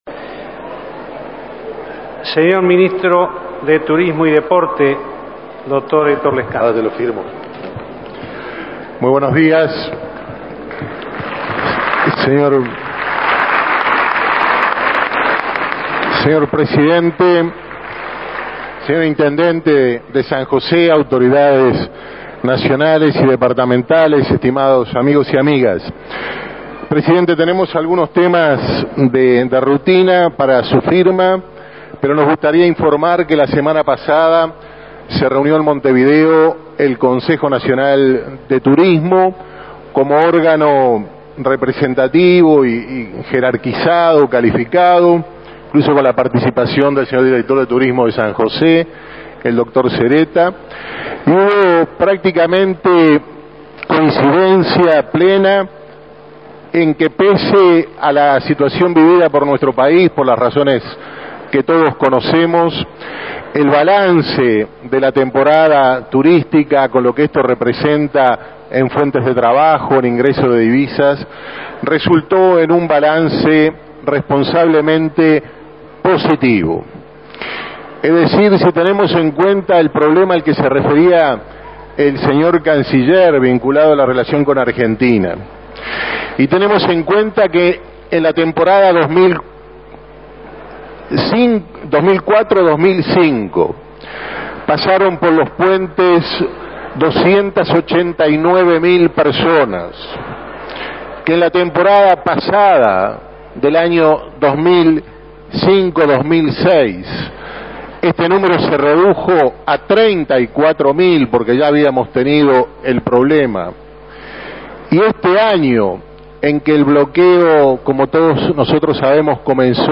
Palabras del Ministro de Turismo y Deporte, Héctor Lescano,en el Consejo de Ministros llevado a cabo en la ciudad de Libertad, departamento de San José.